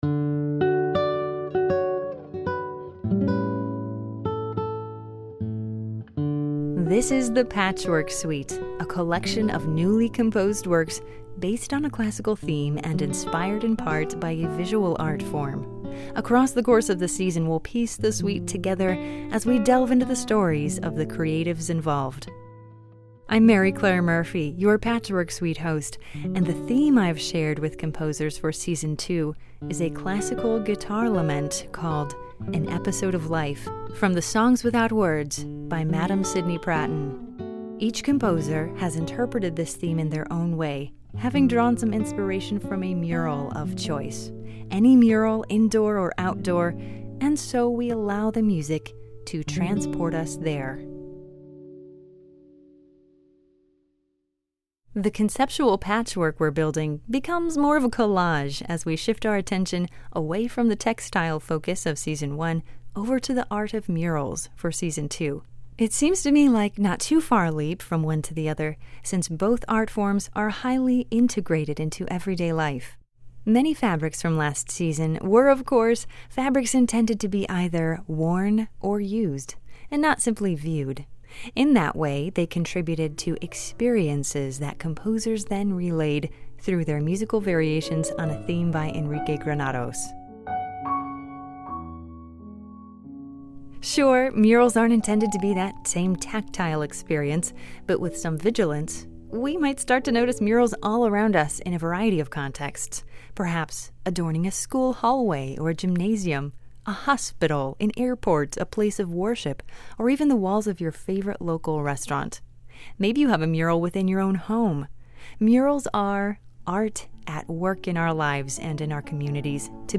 Welcome to The Patchwork Suite Season 2, a theme and variations project based on “An Episode of Life” by Madame Sidney Pratten (1821-1895).